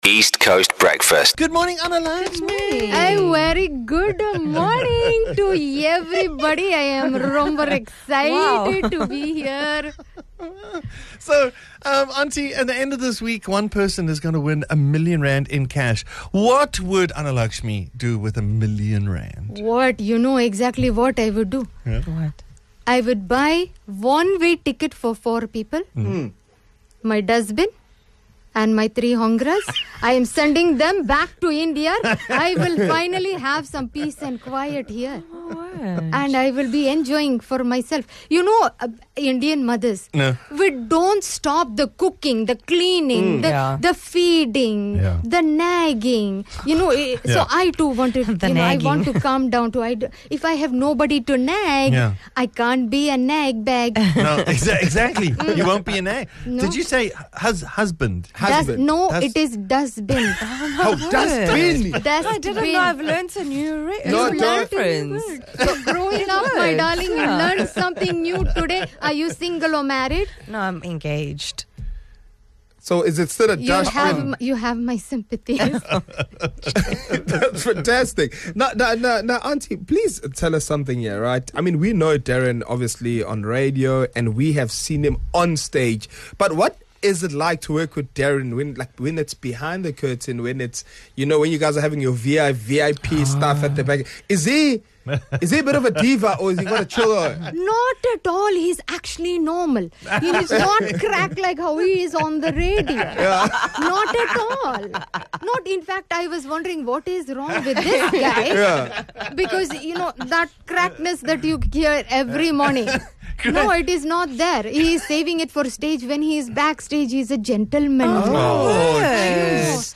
The East Coast Radio Breakfast Show is a fun, and hyperlocal radio show that will captivate and entertain you.
Tune in to the show for an energizing start to your day, accompanied by a fantastic selection of music that will keep you hooked.